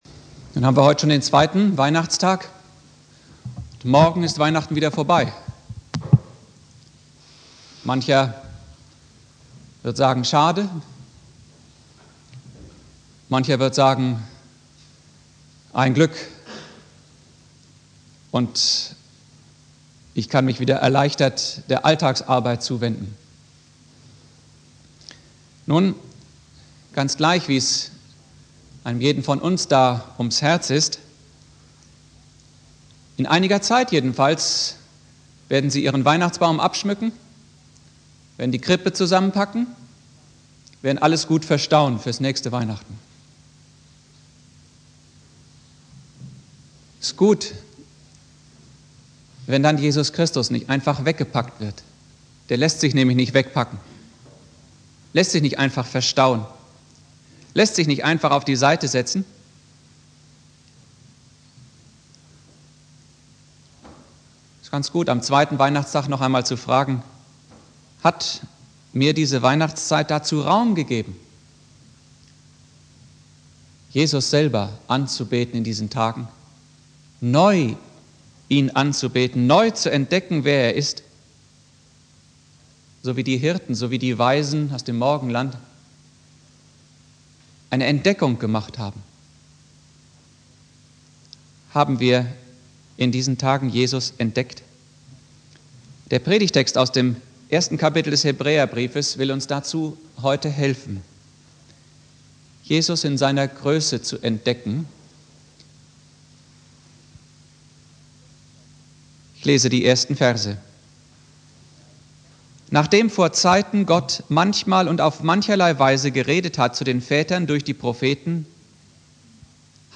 Predigt
2.Weihnachtstag